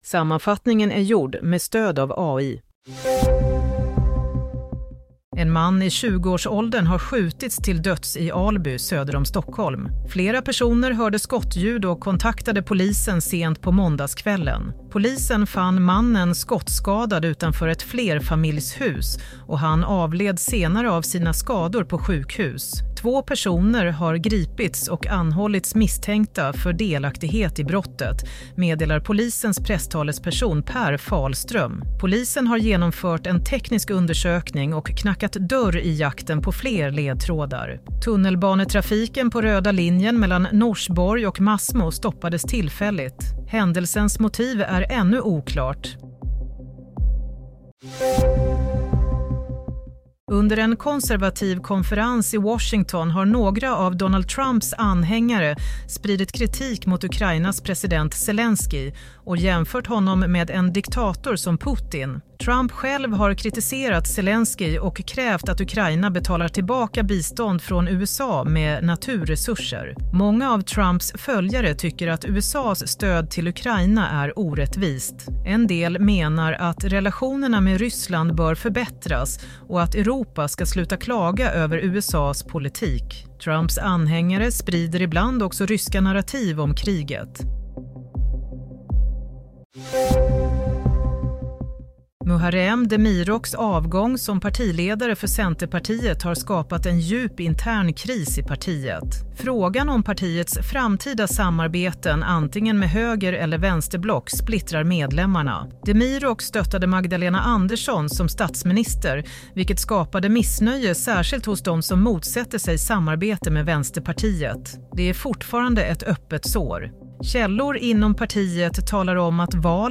Nyhetssammanfattning - 25 februari 07:00